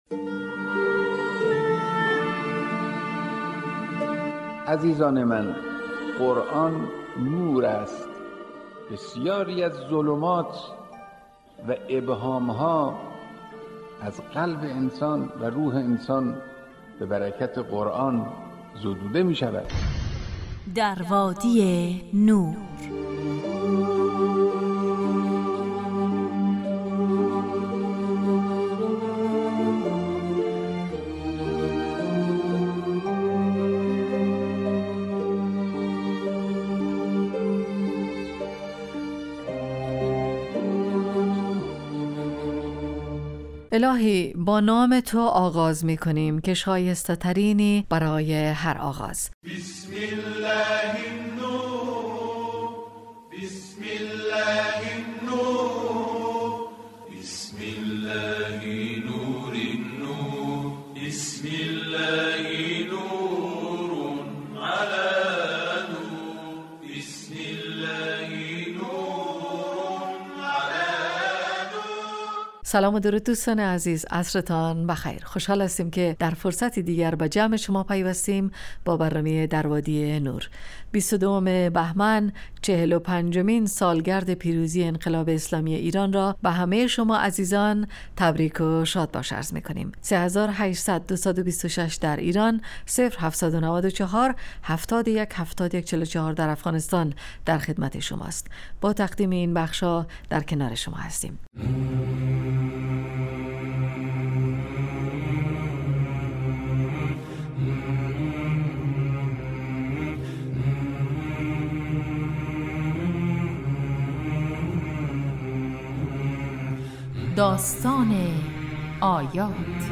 در وادی نور برنامه ای 35 دقیقه ای با موضوعات قرآنی روزهای فرد: ( قرآن و عترت، طلایه داران تلاوت، ایستگاه تلاوت، دانستنیهای قرآنی، تفسیر روان و آموزه های زندگی ساز.